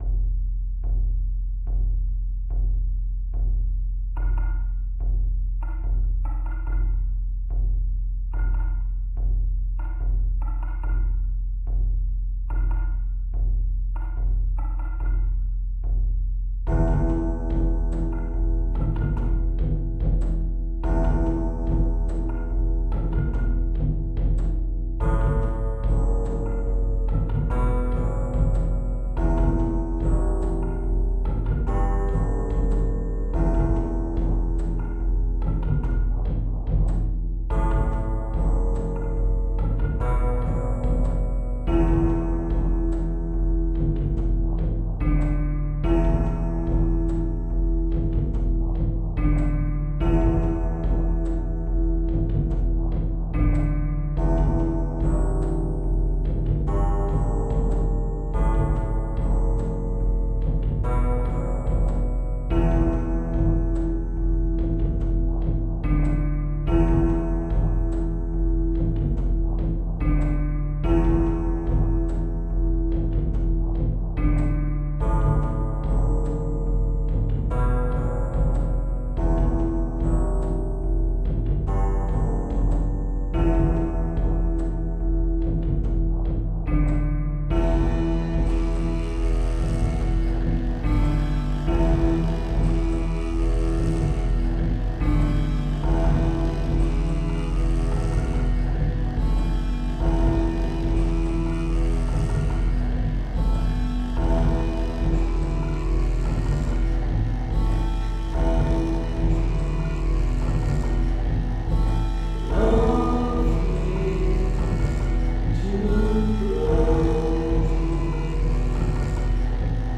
In my mind that phrase was in 5/4: That realisation was the start of the piece as it created the rhythmic frame to illustrate the experience – both the plod of my boots, and the unsteady, odd-beat of unsure feet walking on fresh snow laying on frozen ground.
Try as I might, I couldn’t get that rhythmic loop out of my head, and as it played in my head multiple layers developed; syncopating the feeling of the cold, frozen, silent landscape.
The piece was created in Hydrogen, using my own bespoke instrument rack made of recorded samples. The final version of the track was mixed and vocal added using Audacity.